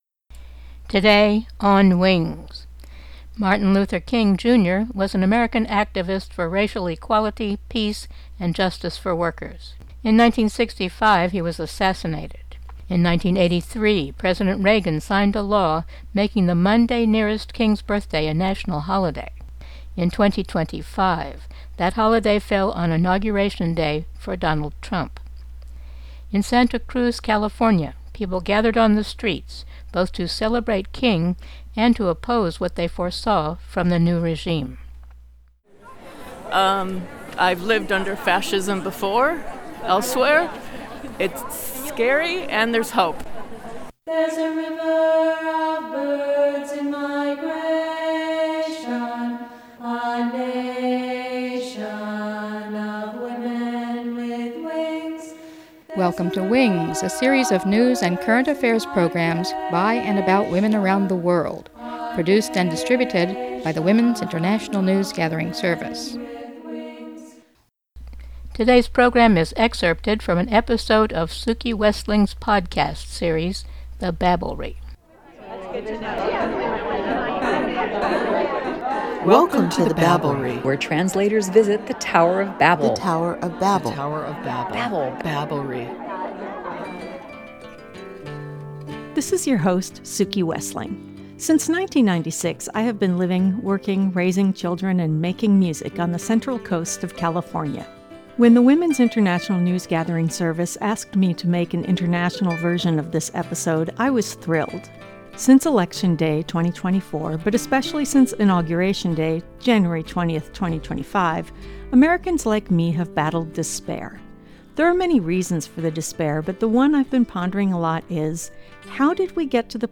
Music recorded live at the event.